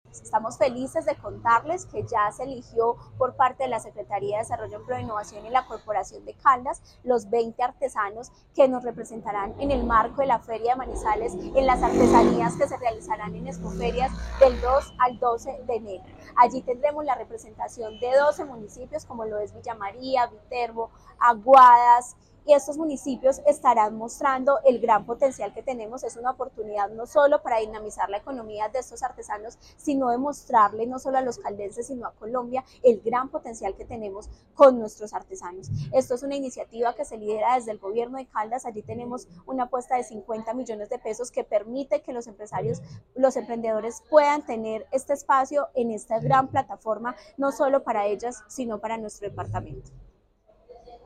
Tania Echeverry Rivera, secretaria de Desarrollo, Empleo e Innovación de Caldas.